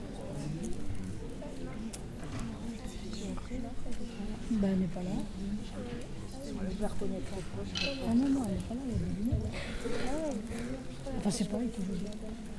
theater.ogg